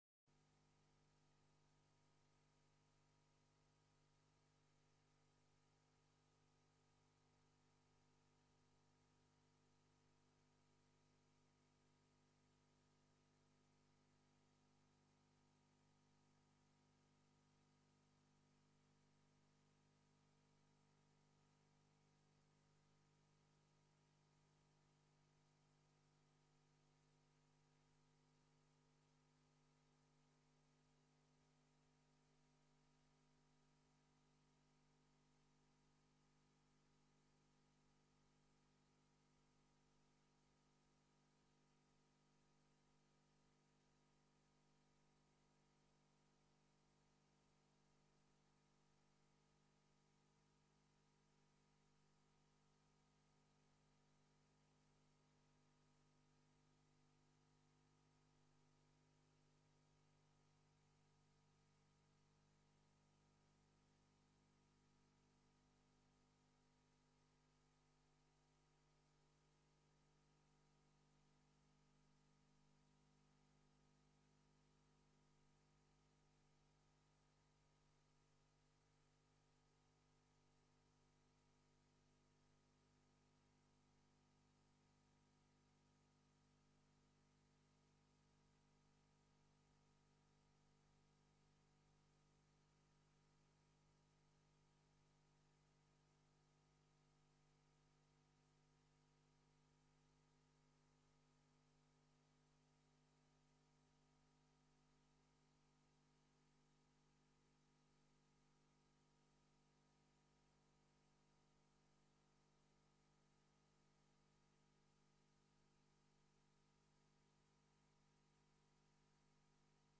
Informatiebijeenkomst 14 november 2017 20:00:00, Gemeente Tynaarlo
Locatie: Raadszaal
Opening en welkom door Wethouder H. Lammers